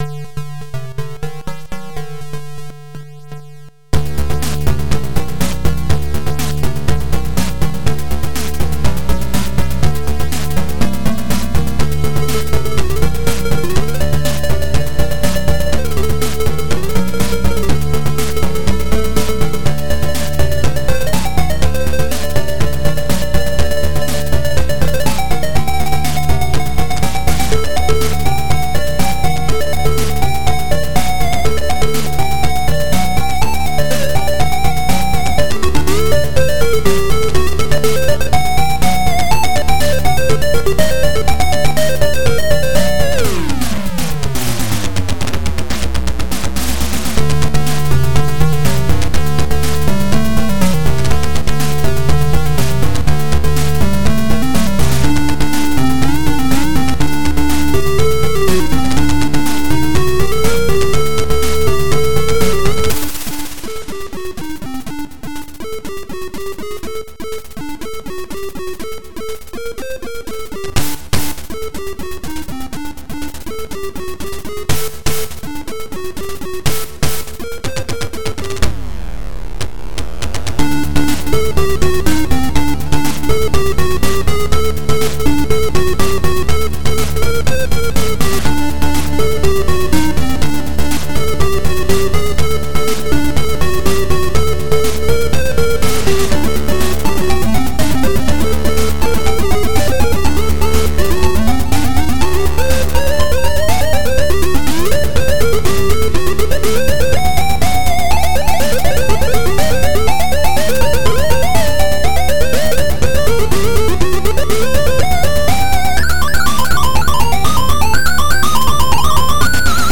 • An allocated channels for playing by default is ABC
ZX Spectrum + TS
• 2x Sound chips AY-3-8912 / YM2149